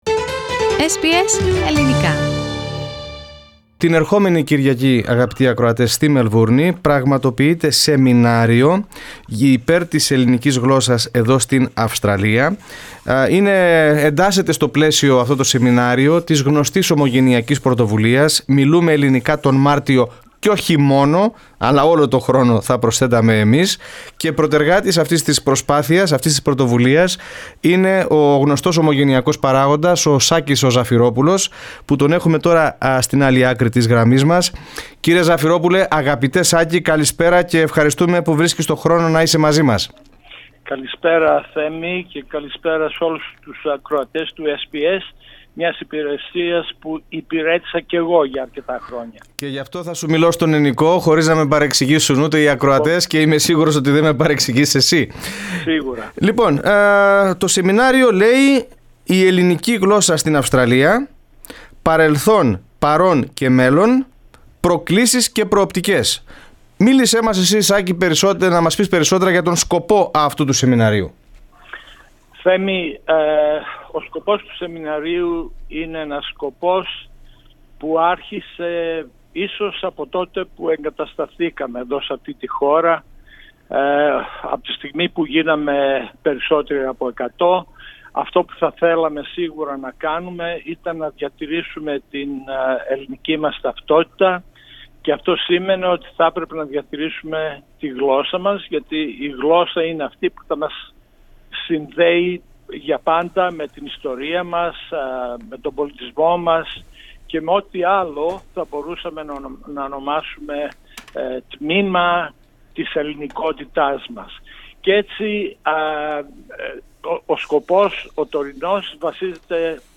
Press Play to hear the full interview in Greek.